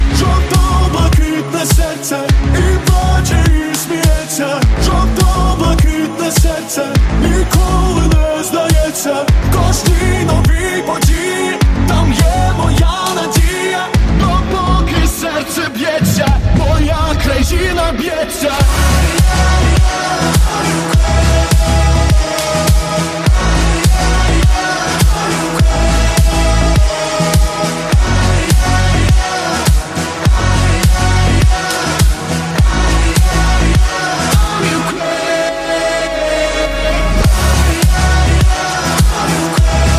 • Качество: 128, Stereo
патриотические
воодушевляющие